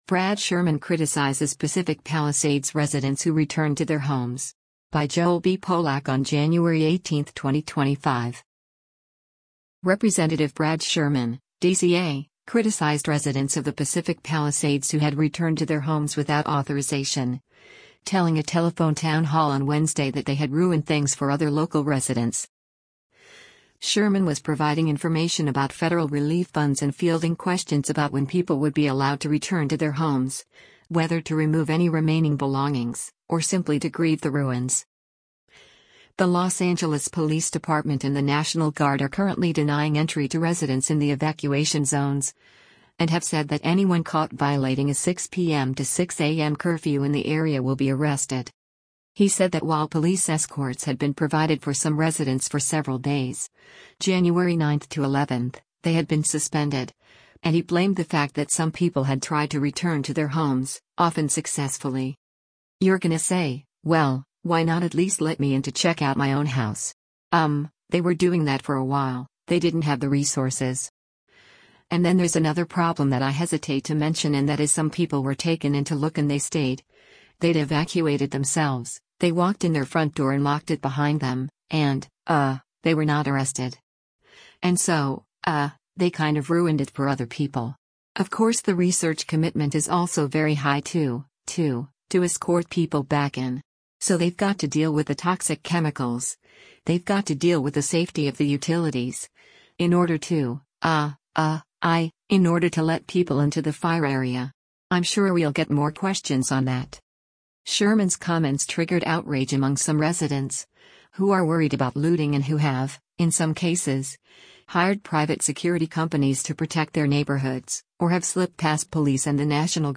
Rep. Brad Sherman (D-CA) criticized residents of the Pacific Palisades who had returned to their homes without authorization, telling a telephone town hall on Wednesday that they had “ruined” things for other local residents.